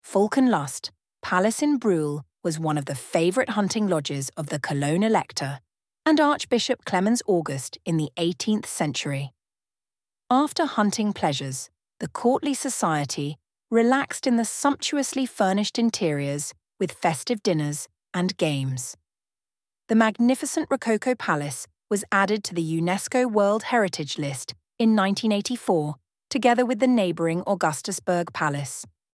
audioguide-english-falkenlust-palace.mp3